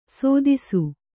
♪ sōdisu